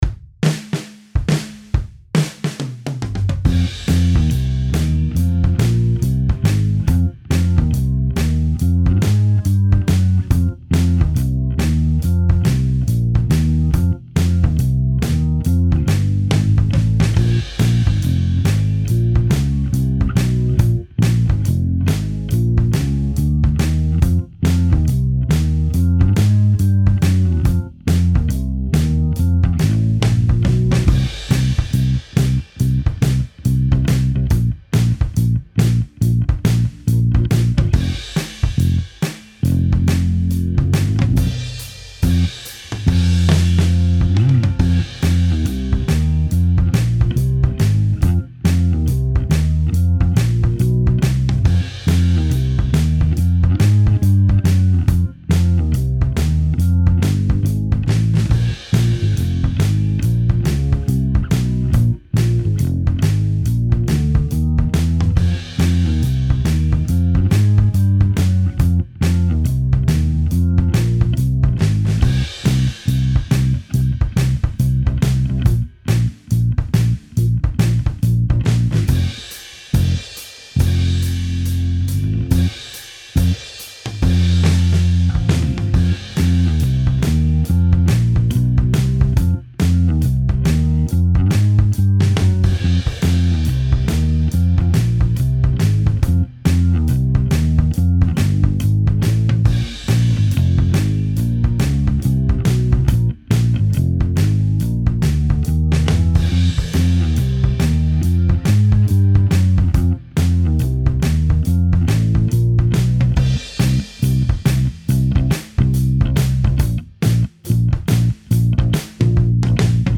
Os vamos a dejar este backing track que puede recordarte a bandas de Blues Rock como Jimi Hendrix, Deep Purple, etc. , para que practiquéis vuestras propias ideas y no solo eso, sino que puedes tocar licks de tu repertorio aprendidos de otros guitarristas.
El Backing Track comienza en el minuto 1:26